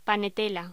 Locución: Panetela
República Dominicana
Cuba
voz